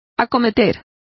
Complete with pronunciation of the translation of undertake.